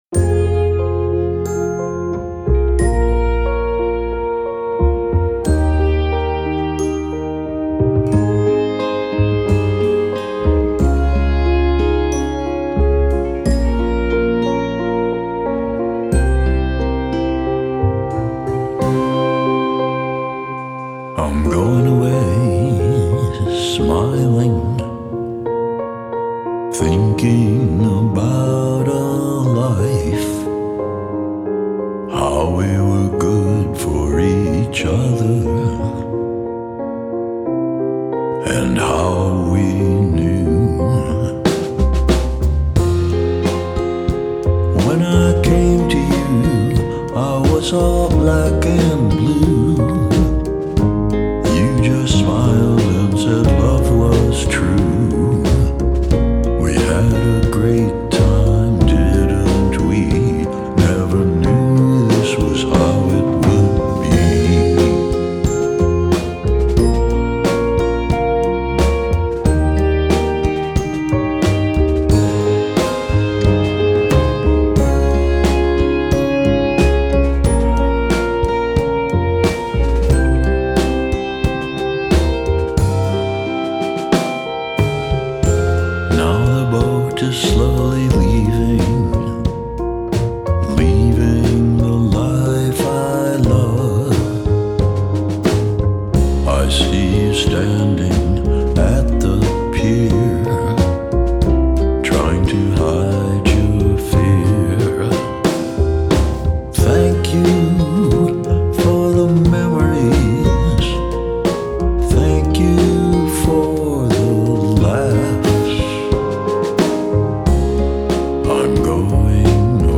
Genre: Vintage Lounge, Chanson, Vocal Jazz